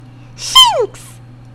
infinitefusion-e18/Audio/SE/Cries/SHINX.mp3 at releases-April